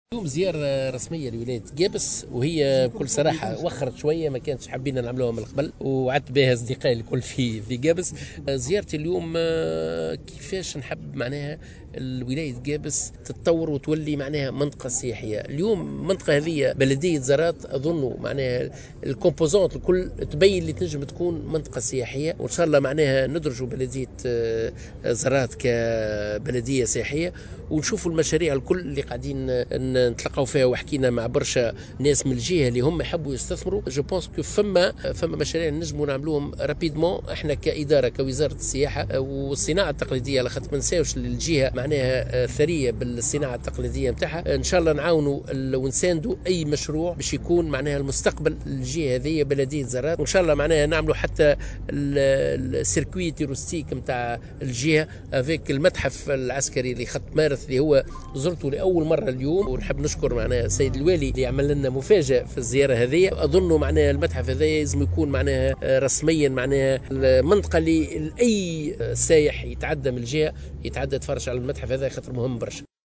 وأضاف الوزير خلال زيارته إلى ولاية قابس في تصريح لمراسل "الجوهرة أف أم" في الجهة، أنه سيتم توجيه كل السياح القادمين للمنطقة إلى المتحف العسكري لخط مارث خاصة وأنه متحف مهّم للترويج للسياحة في المنطقة، وفق قوله.